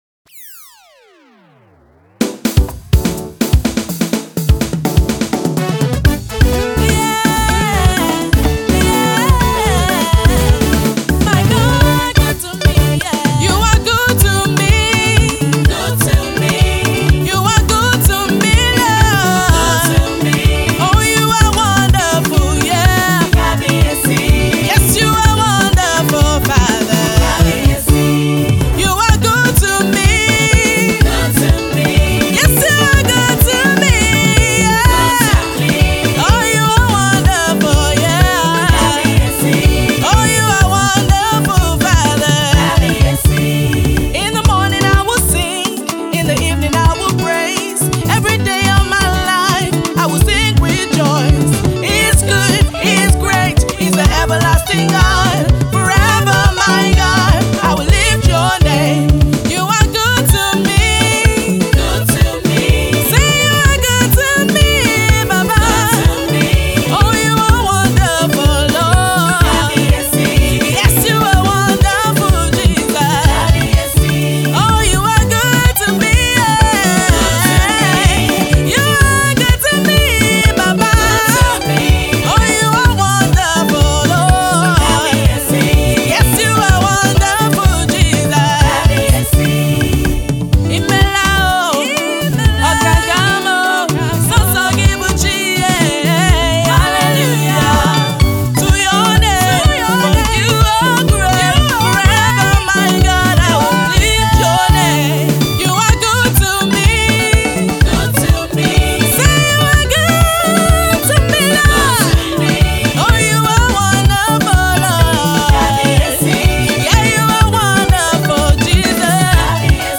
gospel
a praise song